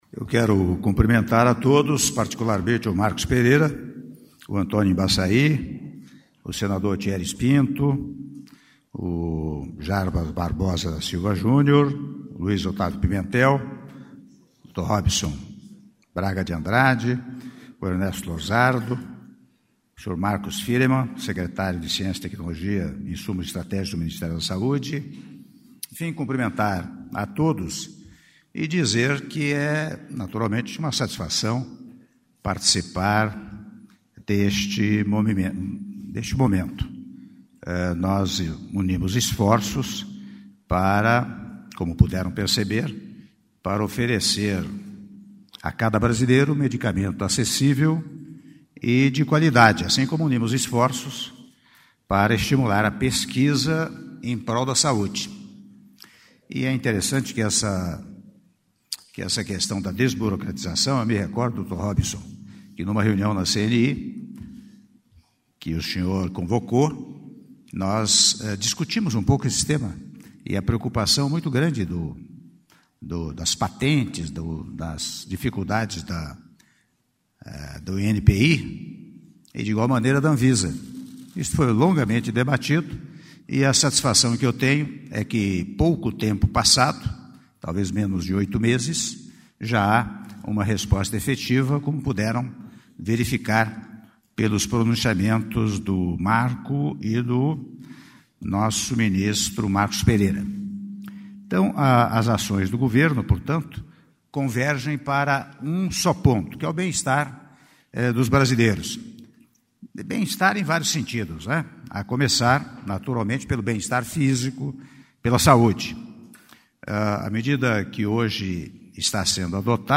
Áudio do discurso do Presidente da República, Michel Temer, durante cerimônia de assinatura de Portaria Conjunta ANVISA/INPI: Modernização e Desburocratização do Sistema de Propriedade Intelectual no Brasil - Brasília/DF (08min38s)